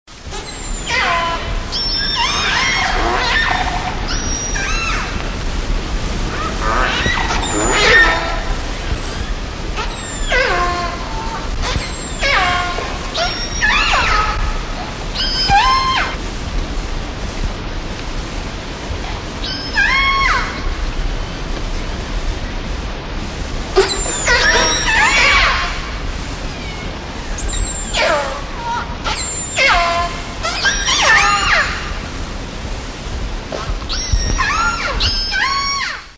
Resident killer whale 2
Category: Animals/Nature   Right: Personal